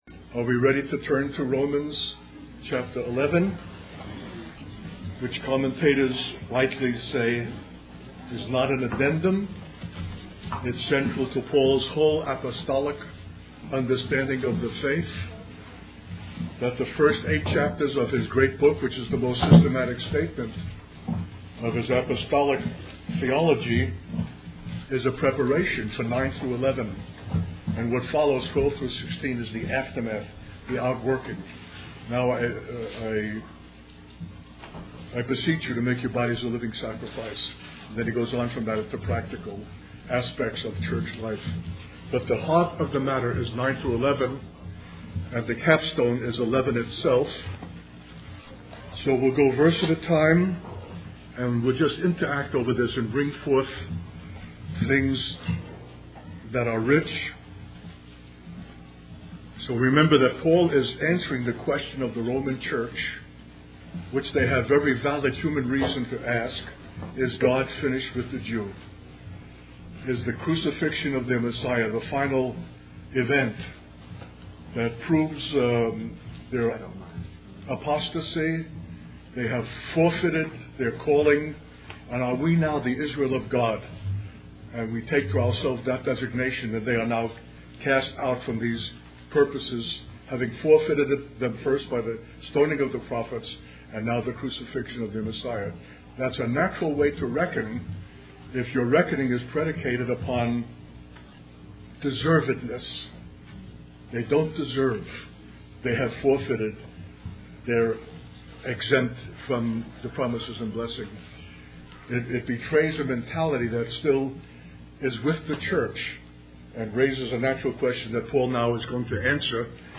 In this sermon, the speaker emphasizes the continuous nature of God and the importance of knowing, celebrating, worshiping, and loving Him.